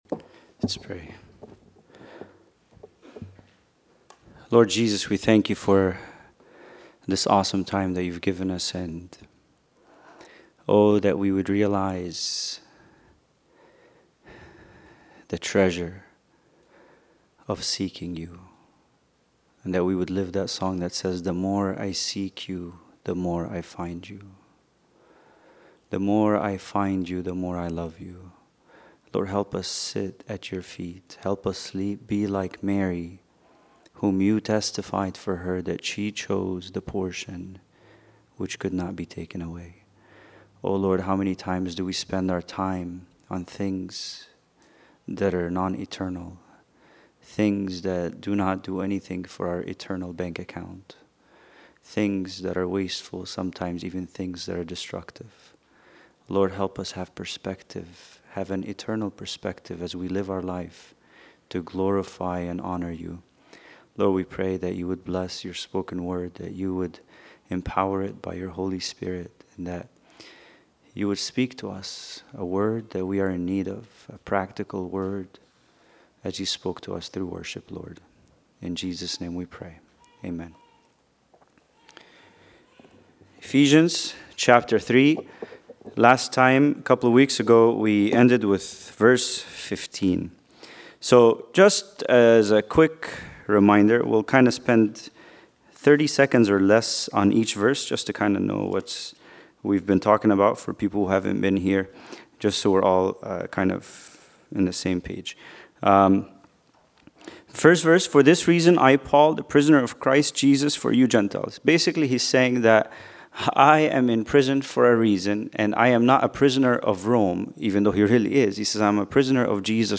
Bible Study: Ephesians 3:16